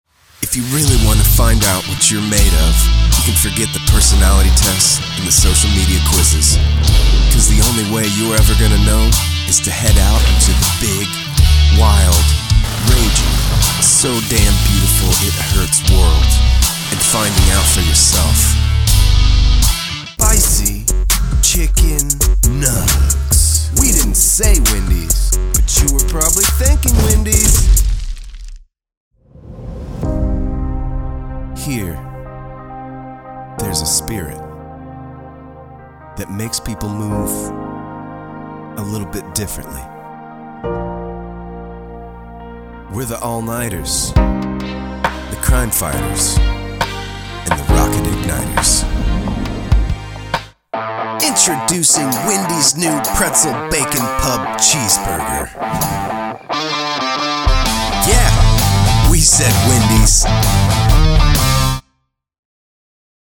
Voice Over / Music / FX – Commercial Audio Demo